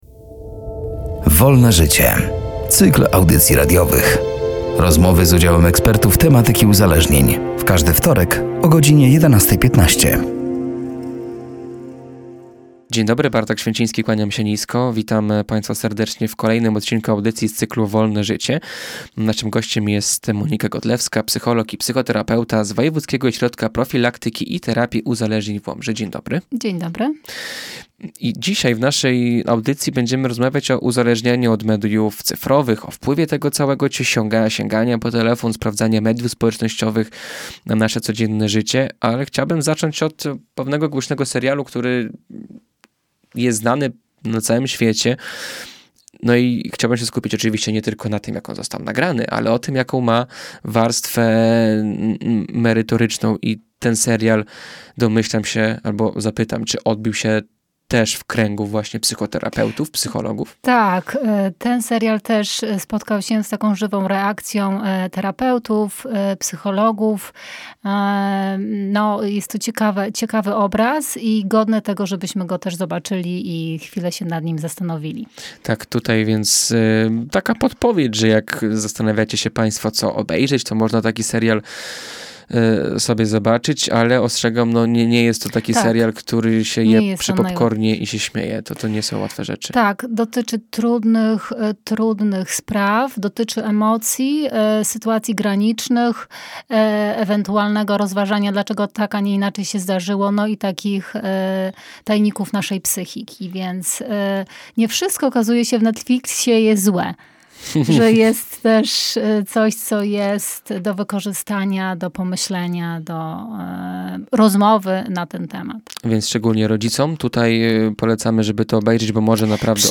„Wolne życie” to cykl audycji radiowych. Rozmowy z udziałem ekspertów z obszaru psychologii i uzależnień.